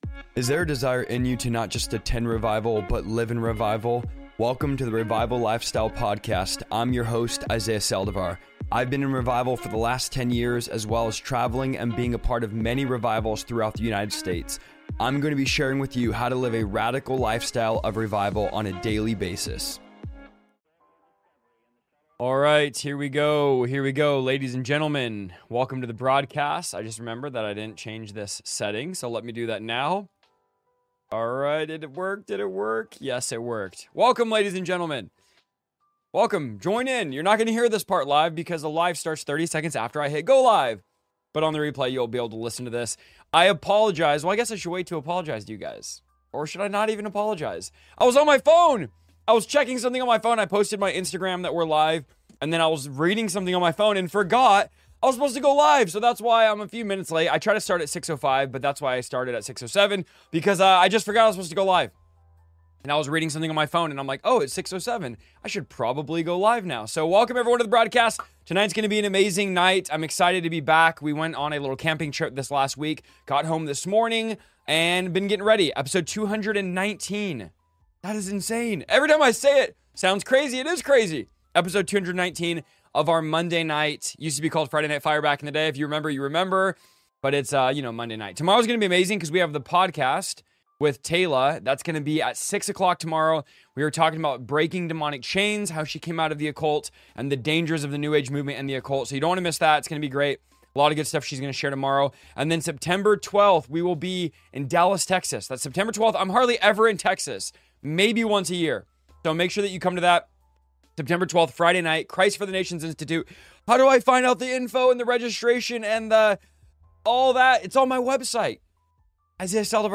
Paul warned Timothy that perilous times would come—and those warnings ring louder than ever today. In this verse-by-verse teaching of 2 Timothy chapters 2 through 4, we dive deep into the Apostle Paul’s final letter, uncovering powerful truths about: